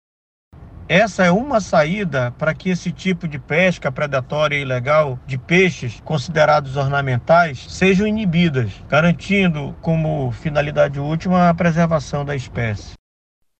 Sonora-Juliano-Valente-diretor-presidente-do-Ipaam.mp3